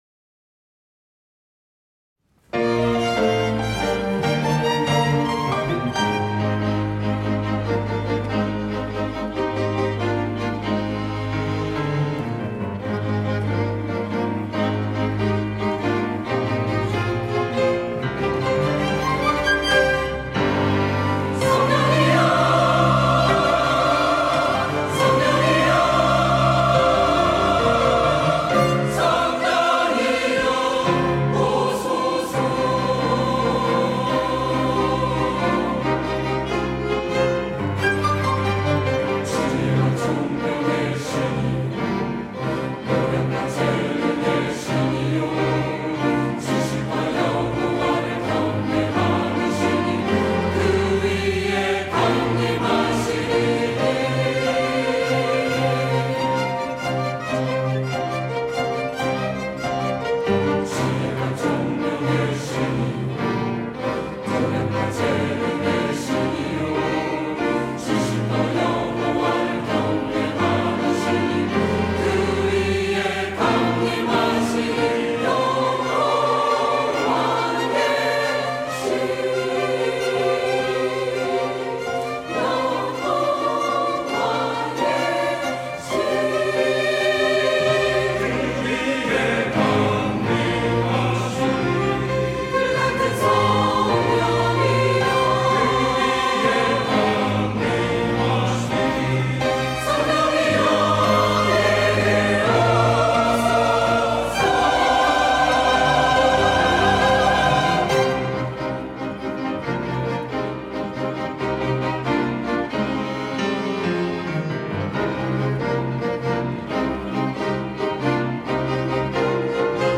호산나(주일3부) - 성령이여 오소서
찬양대